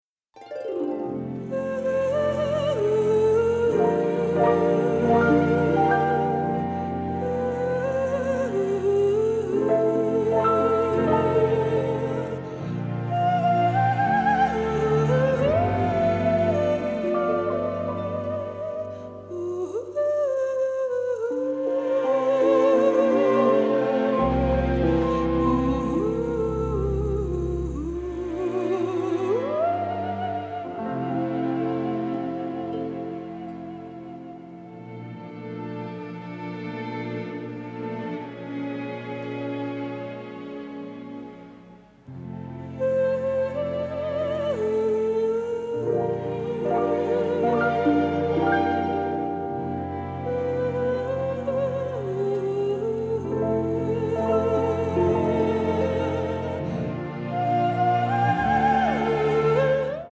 psychedelic cult classics